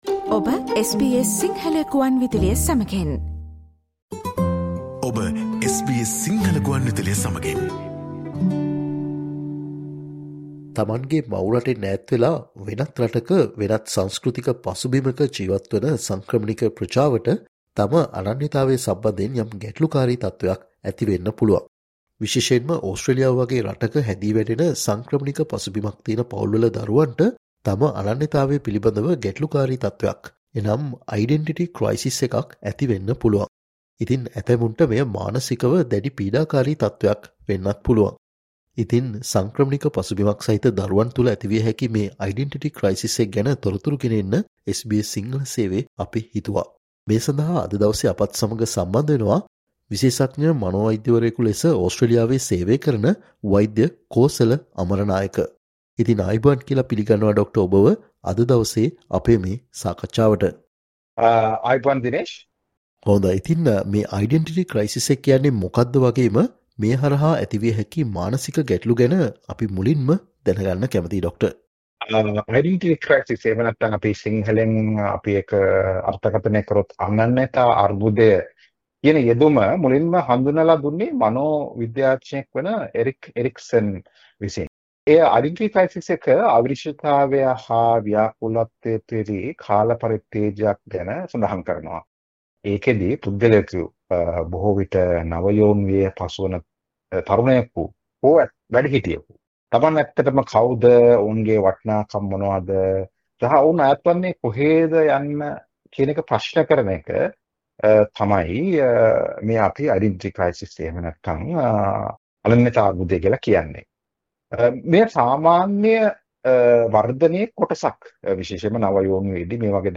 ඔස්ට්‍රේලියාව වගේ රටක හැදී වැඩෙන සංක්‍රමණික පසුබිමක් සහිත දරුවන් මුහුණ දෙන අනන්‍යතාවය පිලිබඳ ගැටළු එසේත් නැතිනම් "Identity Crisis" කියන මානසික ගැටළුකාරී තත්වය සහ එය හඳුනා ගැනීමට දෙමාපියන් වශයෙන් සිදුකල හැකි දෑ පිළිබඳව SBS සිංහල සේවය සිදුකල සාකච්චාවට සවන් දෙන්න.